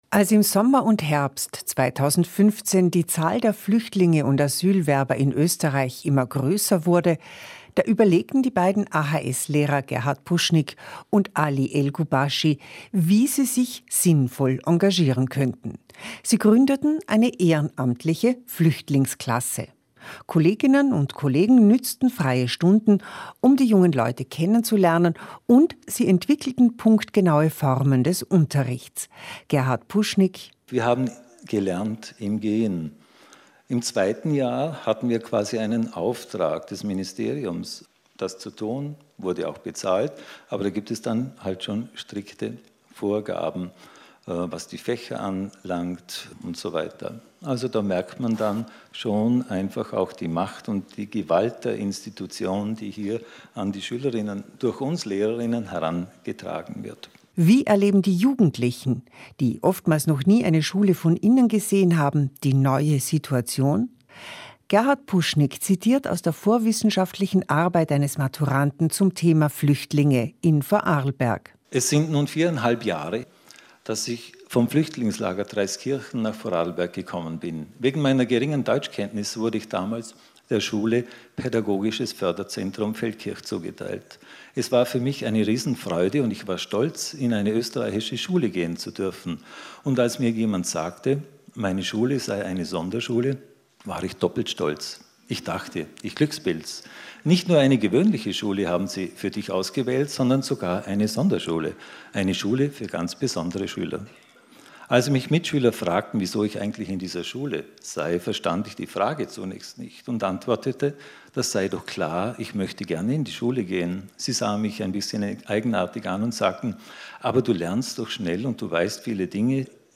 16.45 Uhr, Gespräch